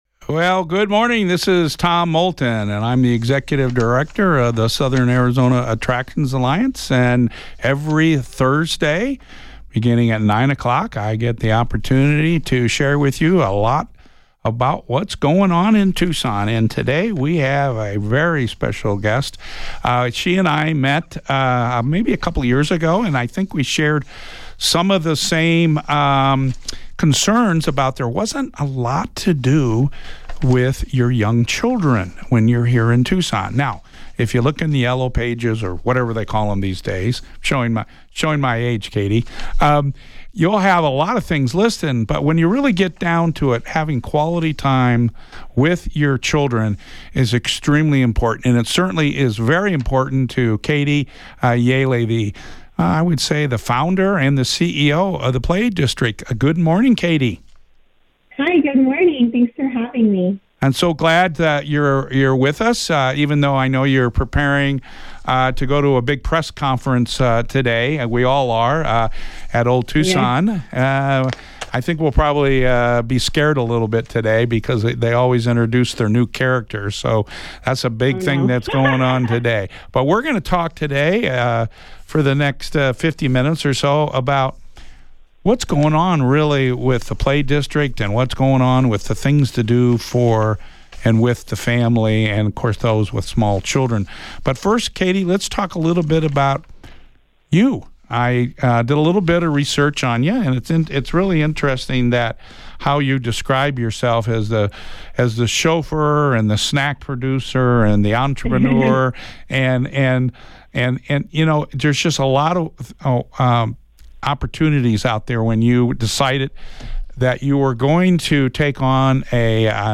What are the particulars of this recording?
Based on the 8/15/24 Jump In Tucson Show on KVOI-1030AM in Tucson, AZ.